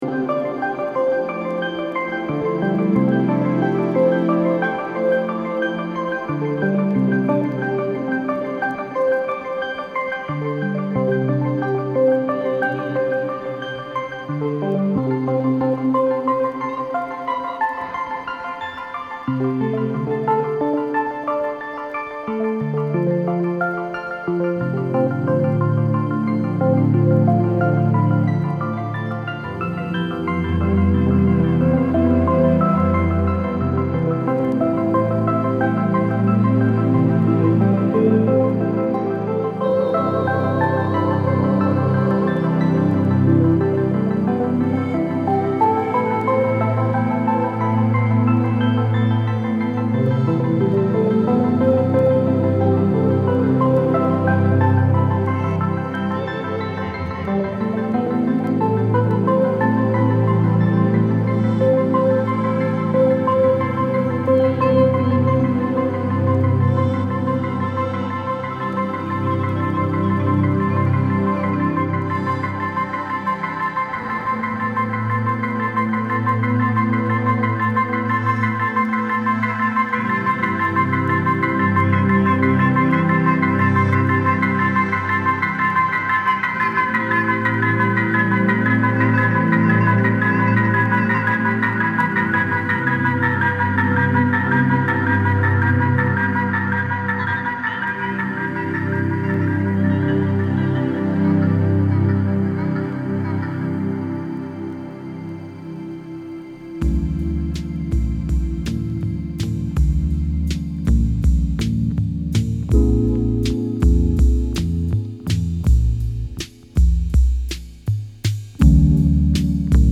ダイジェストになります。